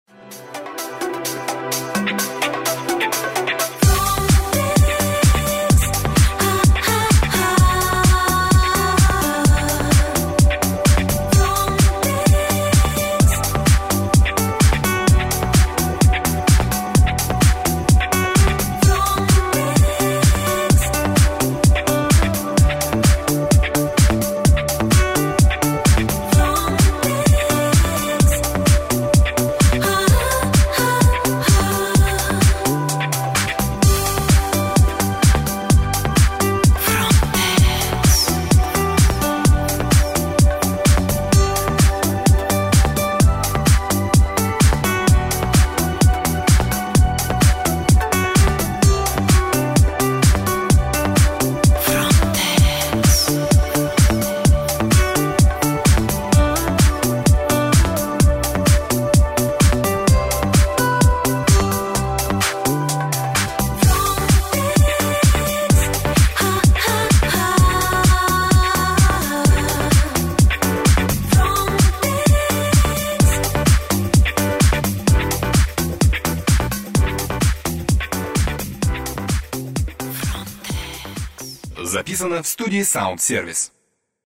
Ниже приведены несколько примеров продакт плейсмент, созданных в студии звукозаписи «СаундСервис»: